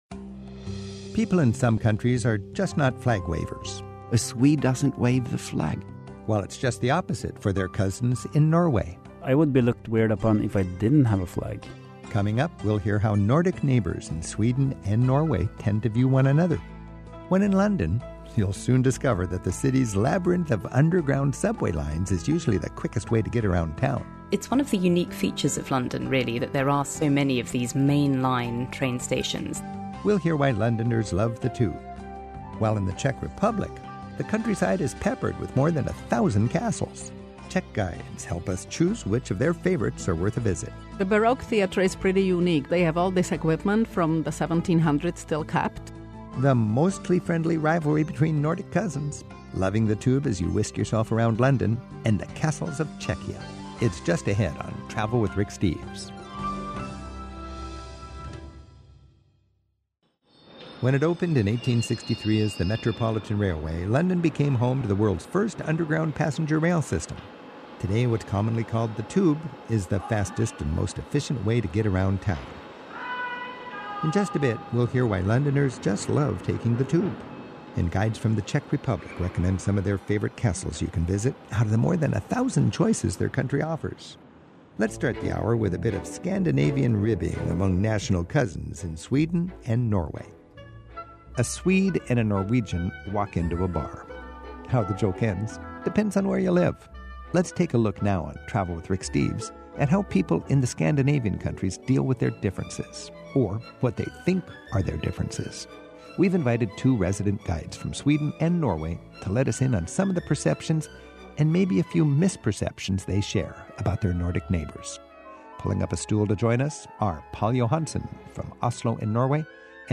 My Sentiment & Notes 806 Swedish-Norwegian Cousins; The London Tube; Czech Castles Podcast: Travel with Rick Steves Published On: Sat Sep 27 2025 Description: Get an inside look at the (mostly) friendly rivalries of Scandinavia as a pair of tour guides from Sweden and Norway sit down for a chat. Then hear from a London Blue Badge guide about what to know — and love — about London's famous underground metro system. And listen in as we explore the grand castles of the Czech Republic.